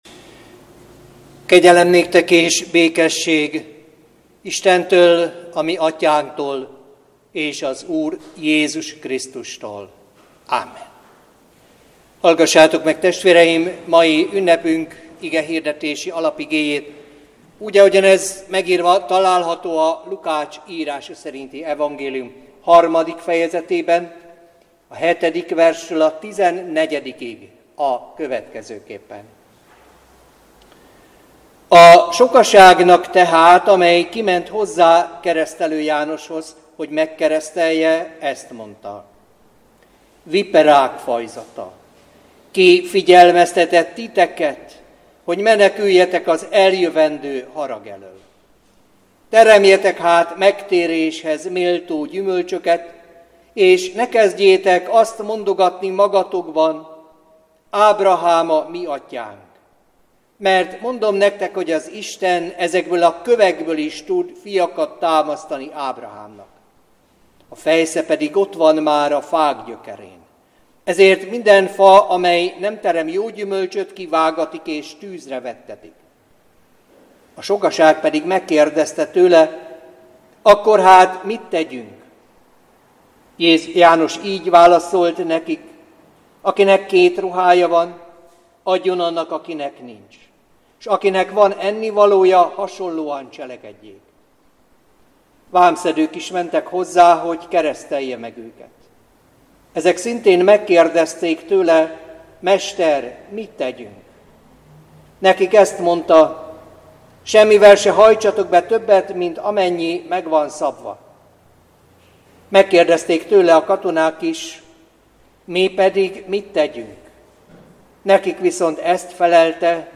Igehirdetések